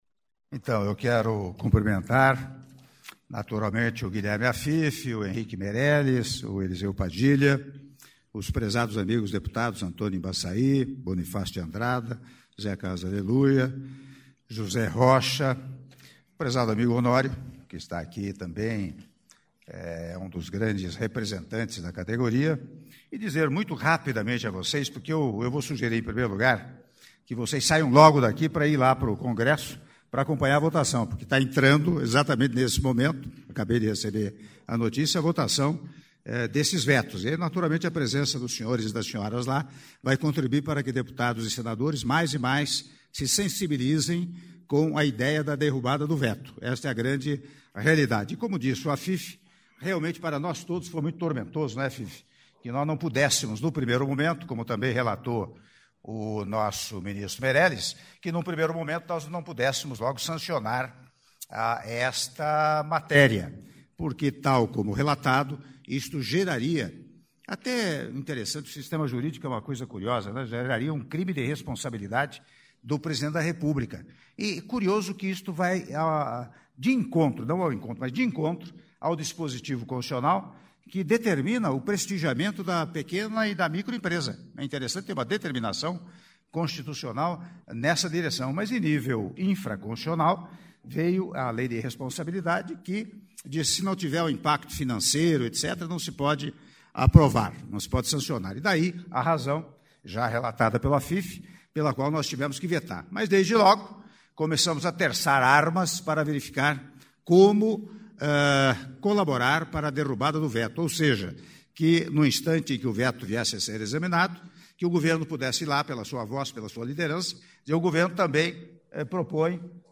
Áudio do discurso do Presidente da República, Michel Temer, durante discussão de pauta do REFIS da micro e pequena empresa - Palácio do Planalto (02min39s)